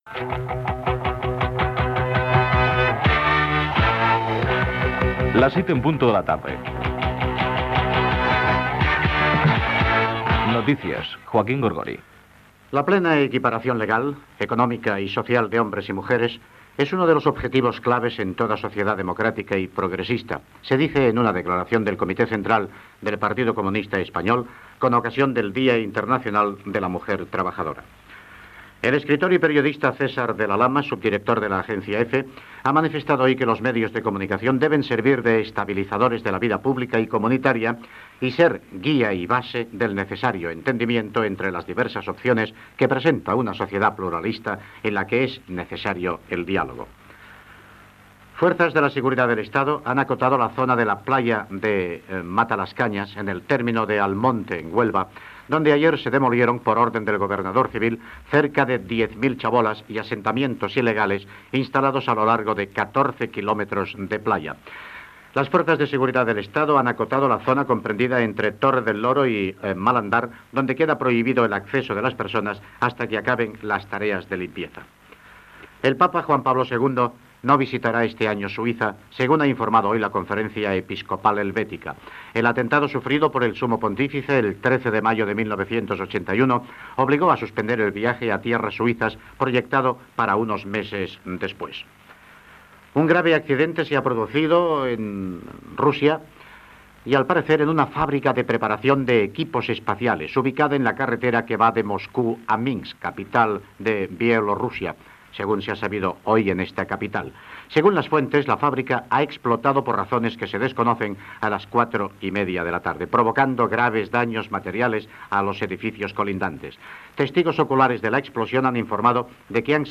Sintonia, hora, declaració del Partido Comunista de España sobre el Dia Internacional de la Dona treballadora, enderrocament de barraques a la platja de Matalascañas, el sant Pare no visitarà Suïssa, explossió en una fàbrica de Minsk. Indicatiu de l'emissora
Informatiu